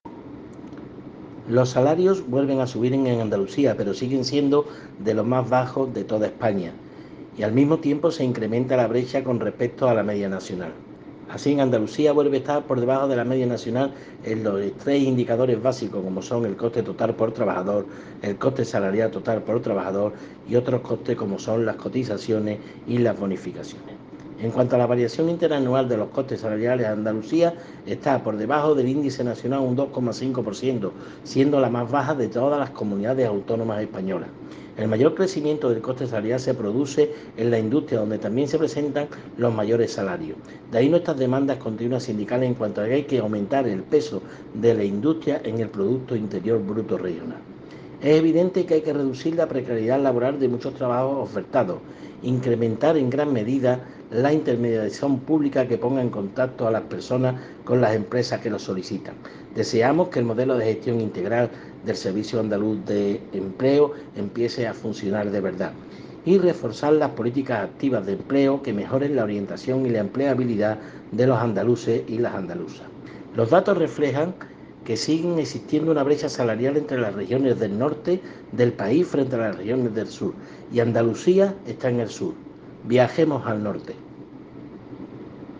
Audio de Valoración